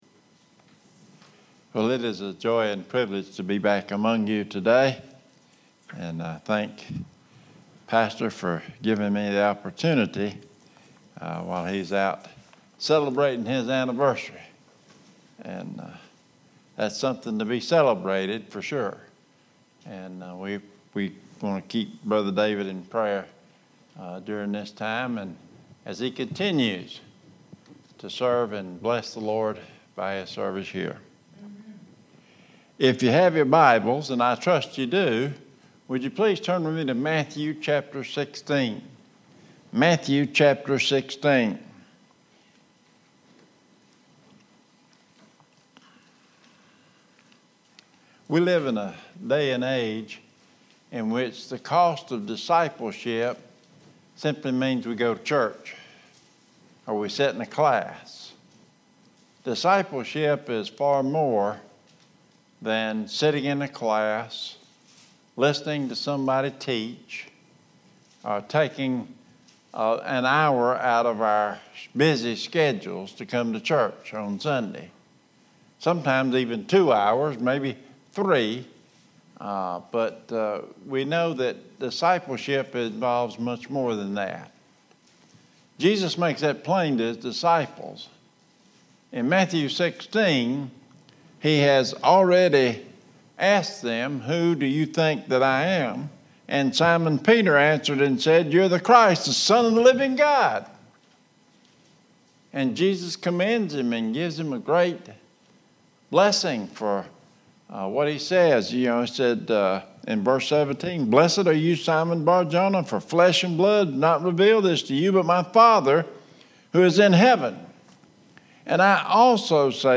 Guest Preaching
Sermon Audio